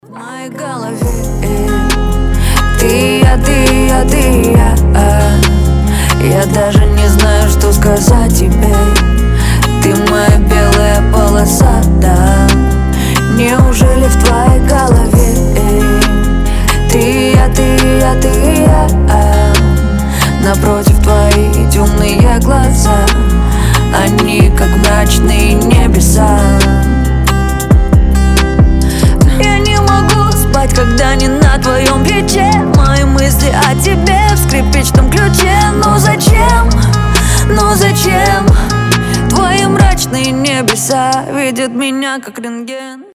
• Качество: 320, Stereo
гитара
мелодичные
женский голос